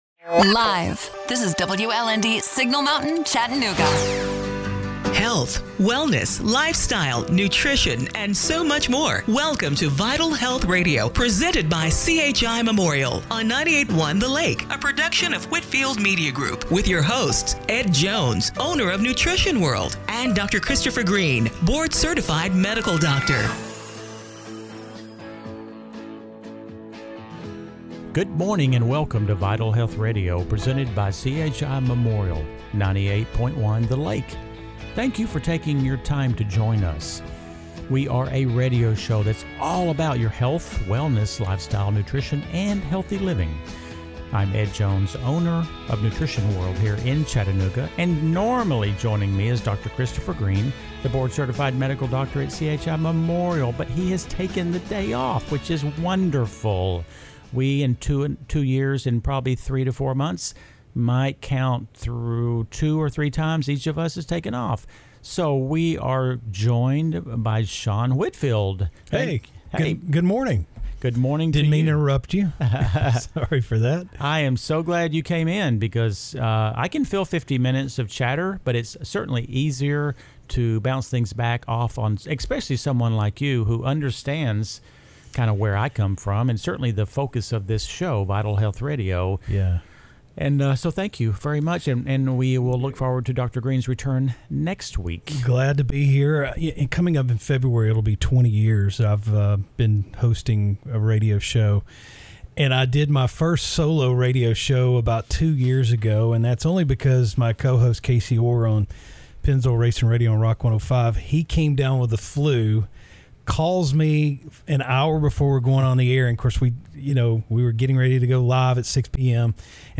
July 19, 2020 – Radio Show - Vital Health Radio